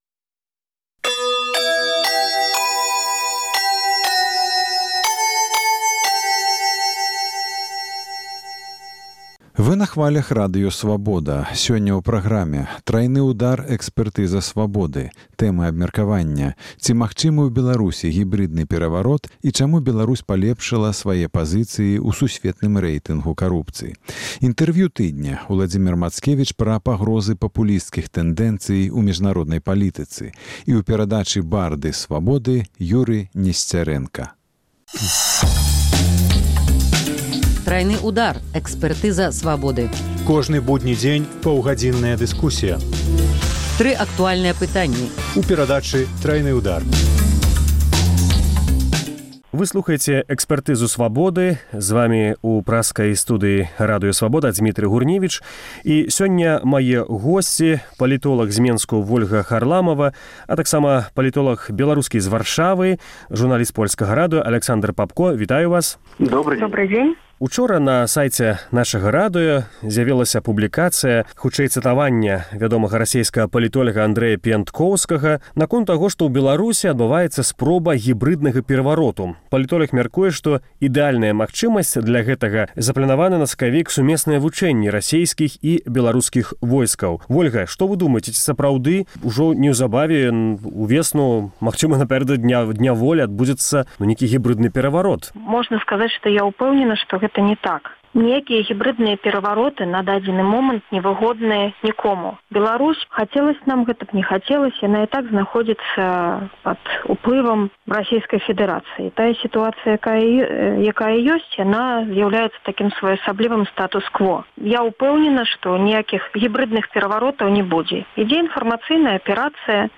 Палітоляг мяркуе, што ідэальная магчымасьць для гэтага – заплянаваныя на сакавік сумесныя вучэньні расейскіх і беларускіх войскаў. Гэтую тэму абмяркоўваюць палітолягі